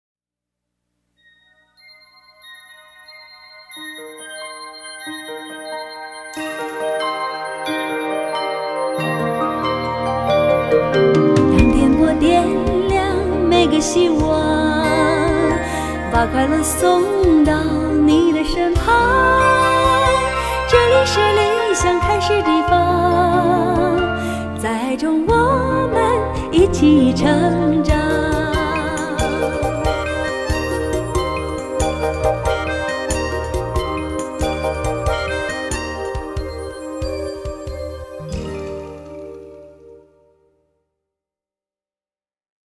TV and Radio Jingles